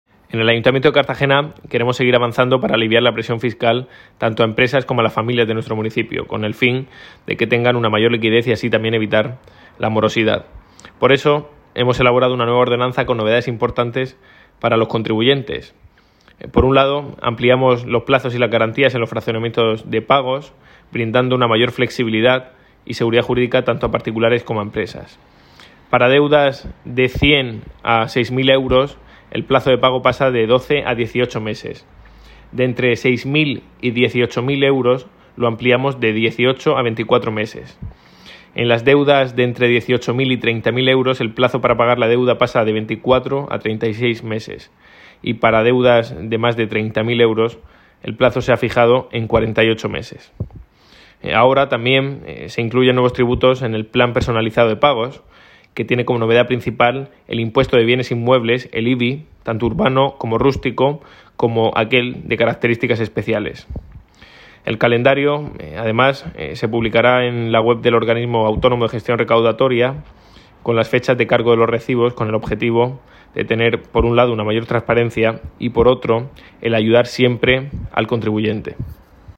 Enlace a Declaraciones de Ignacio Jáudenes